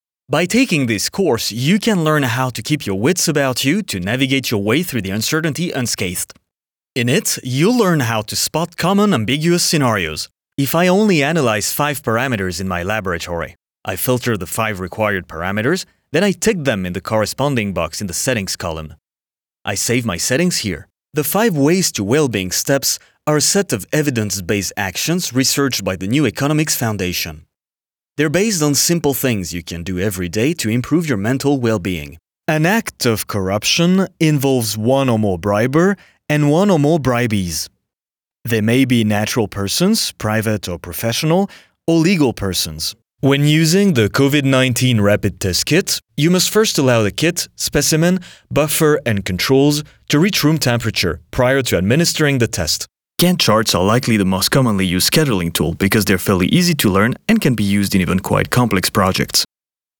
Naturelle, Polyvalente, Fiable, Amicale, Corporative
E-learning
His natural mid-low voice sounds professional and trustworthy but also warm and friendly - perfect for corporate and educational content, while his versatility allows him to voice a wide range of characters and commercials.